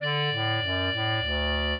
clarinet
minuet15-12.wav